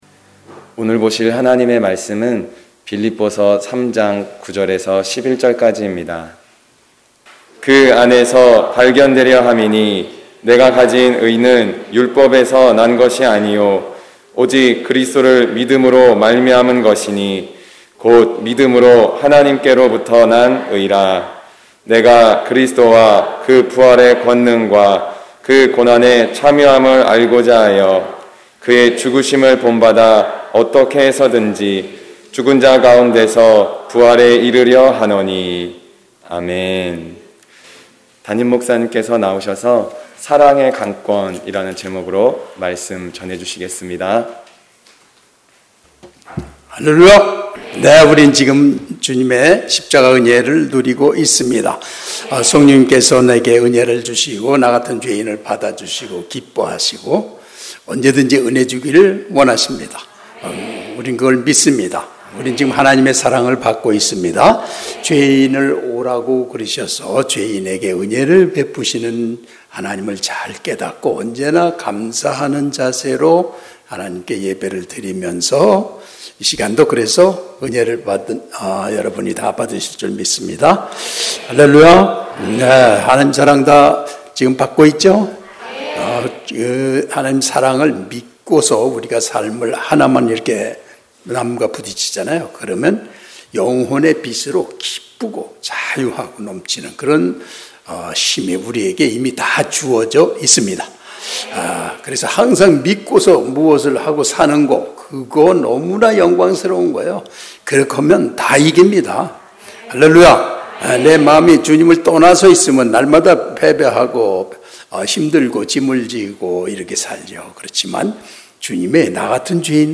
주일11시예배